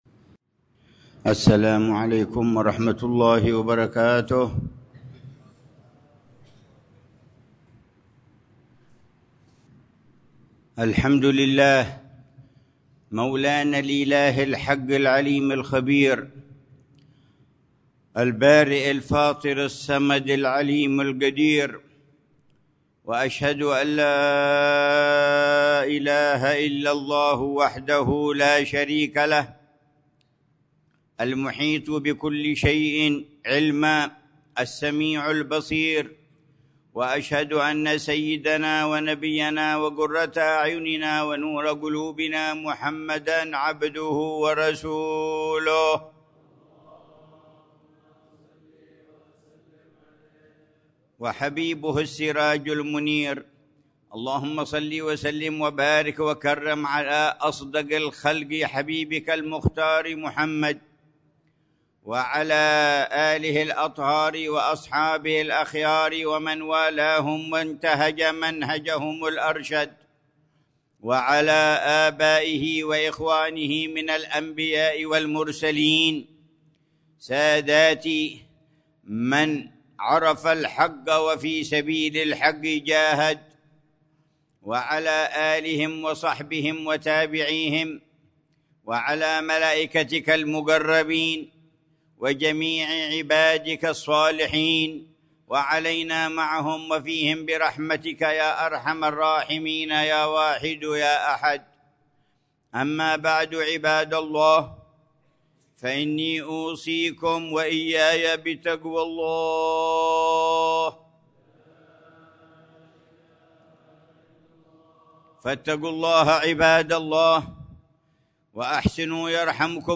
خطبة الجمعة للعلامة الحبيب عمر بن محمد بن حفيظ في جامع الإيمان، بحارة الإيمان، عيديد، مدينة تريم، 20 ربيع الأول 1447هـ بعنوان: